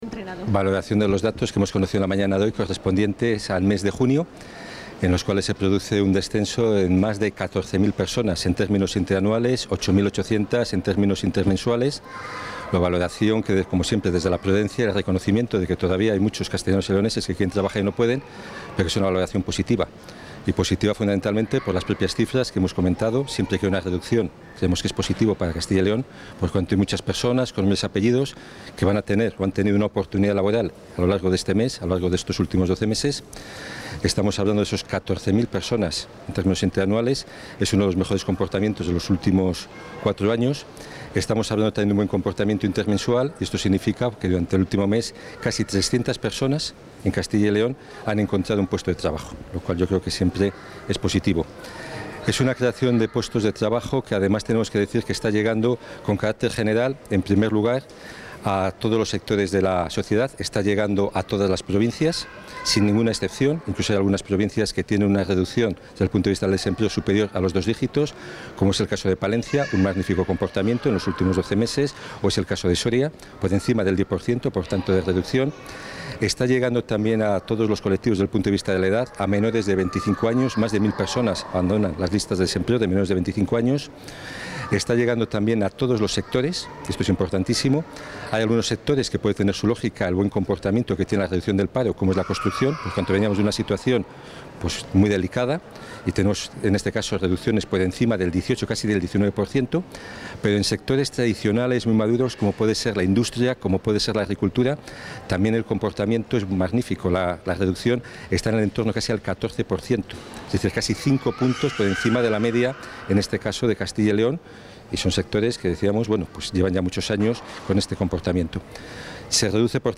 Valoración del viceconsejero de Empleo y Diálogo Social.